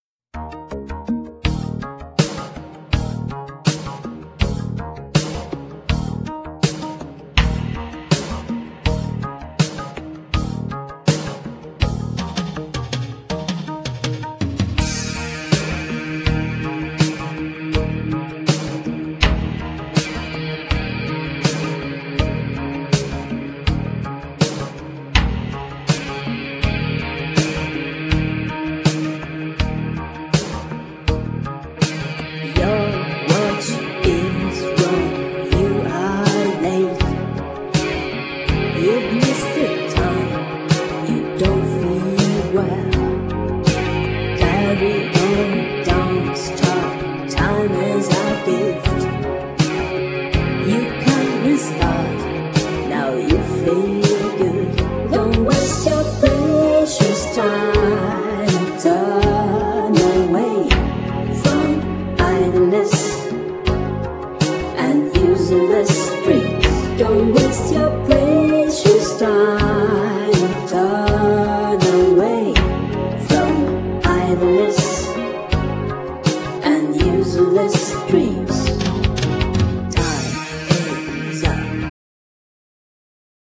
Genere : Pop rock